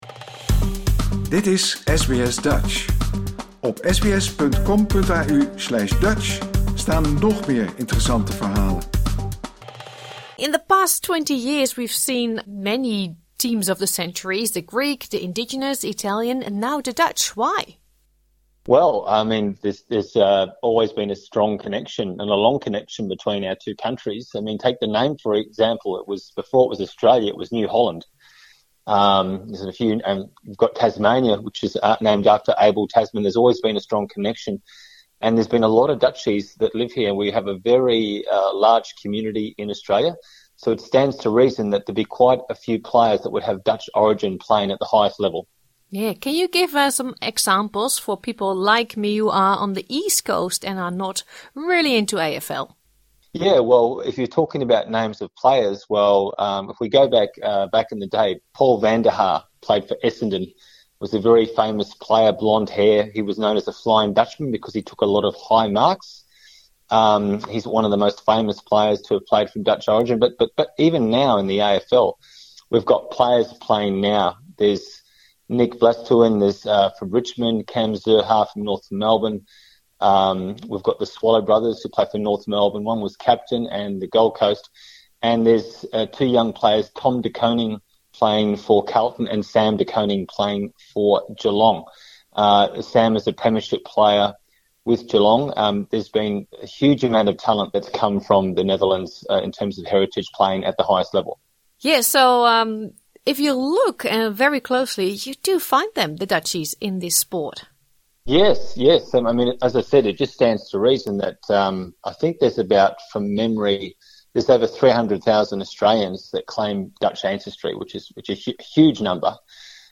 Wij belden hem over dit leuke intitiatief. Dit interview is in het Engels.